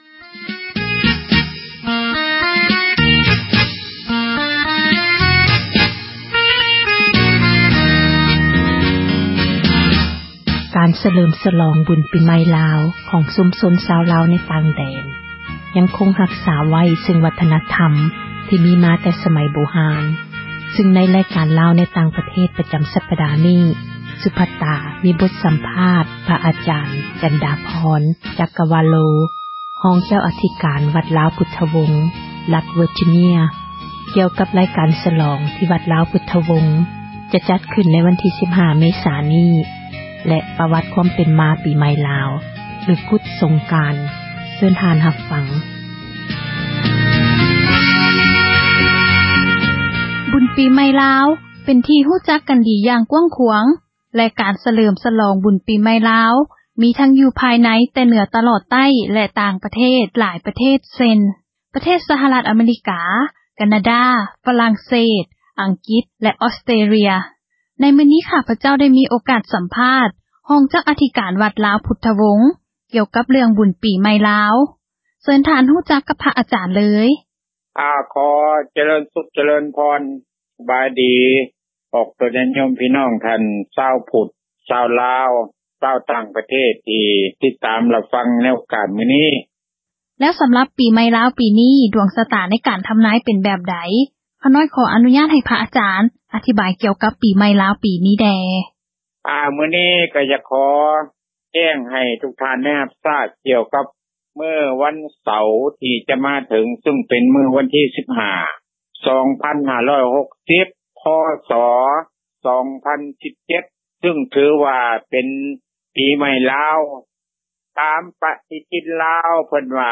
ບົດສັັມພາດ